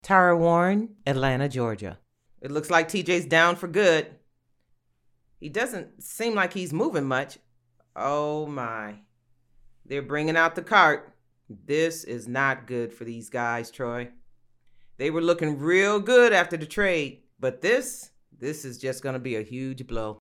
Voice Over Reel